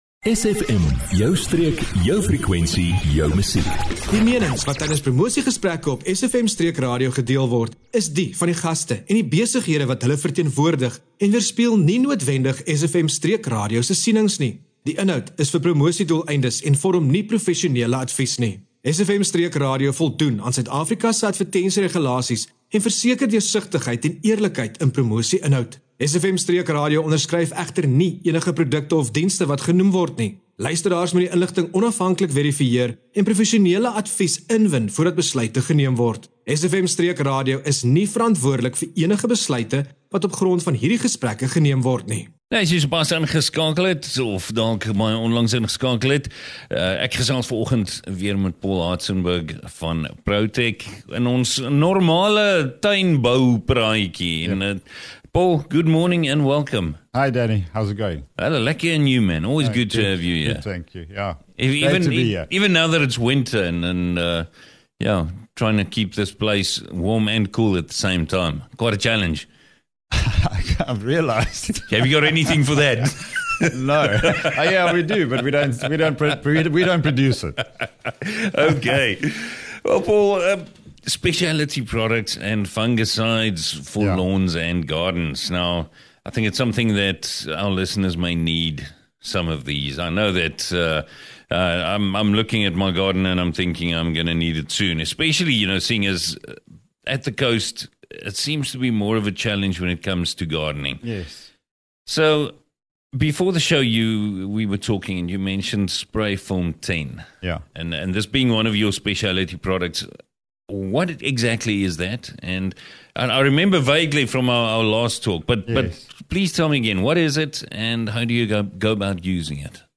💪 🌱🌸 a Practical chat about specialty products and fungicides for your lawn and garden with 💡 Tips for leaf spots, mildew and common garden issues.